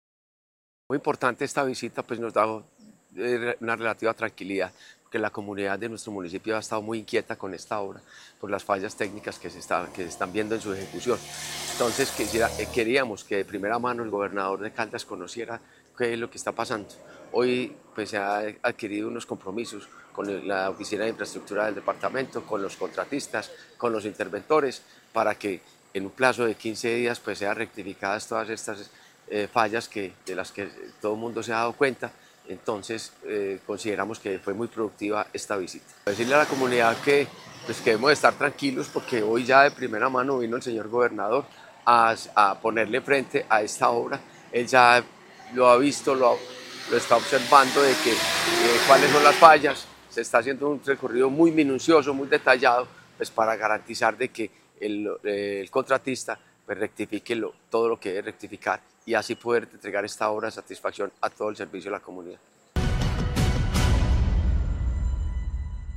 Alcalde de Aguadas, Fabio Gómez Mejía.